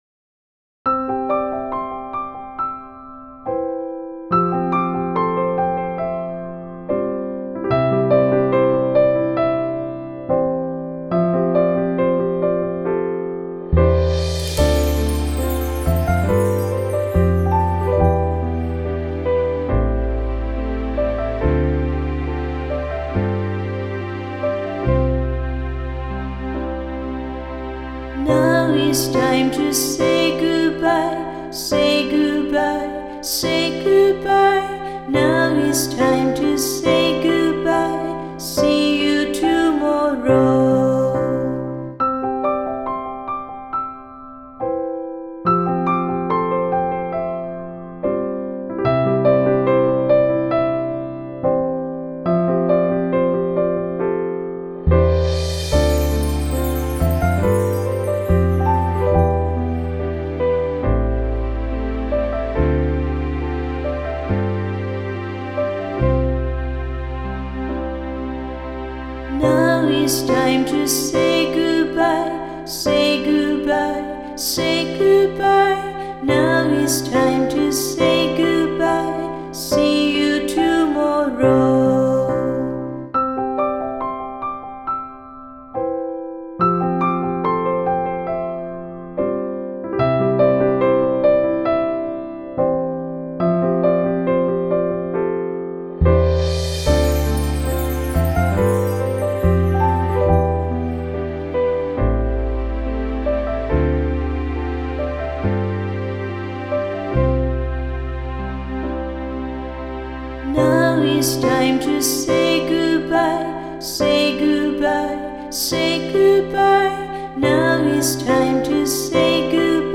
Singing
Fast